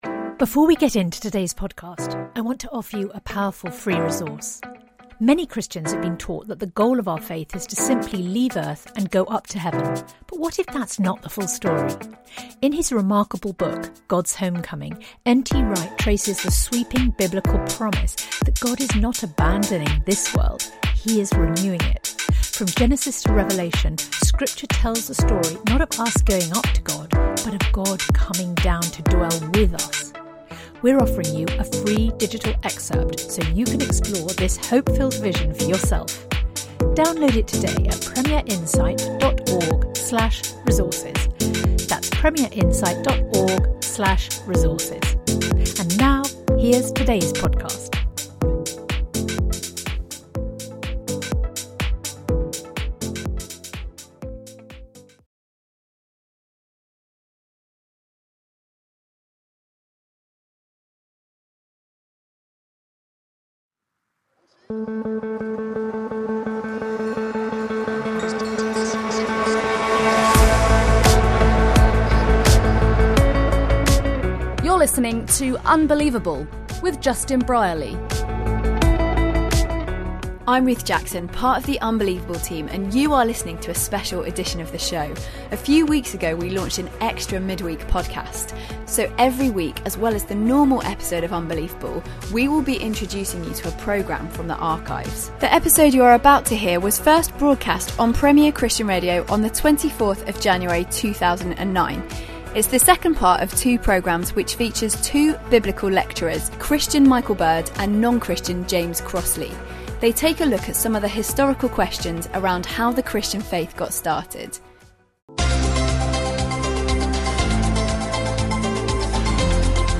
Two New Testament scholars go head to head.
They have different conclusions about how the Christian faith began and have written a dialogue in their book ‘How Did Christianity Begin - a believer and non-Believer examine the evidence’. Originally broadcast in January 2009.